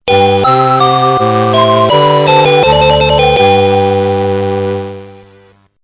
クラシックの名曲をアレンジしたボーナスサウンド！